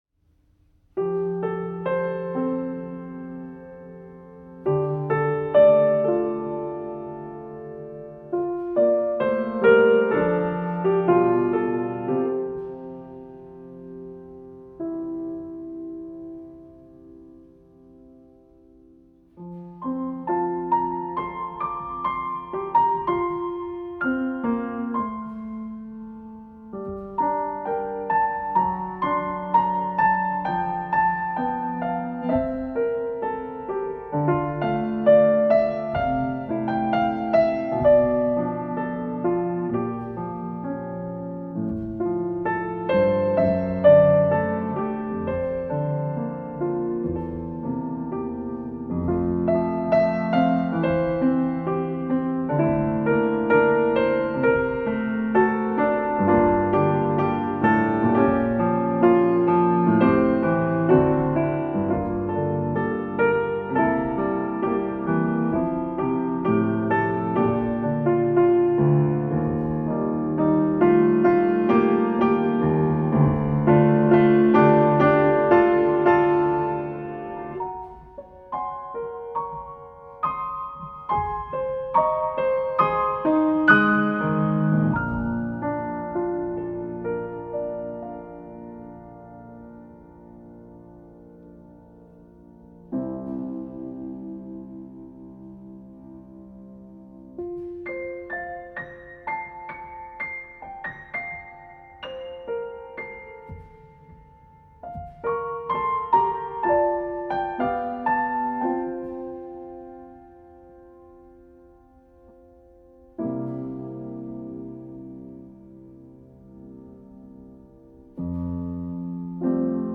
Composed for solo piano
was recorded in October 2023 at Doli Media Studio
The piece was written to capture the essence of love—its serenity, complexity, and emotional depth—through a flowing, introspective musical language characterized by harmonic evolution, interwoven voices, and stepwise bass lines.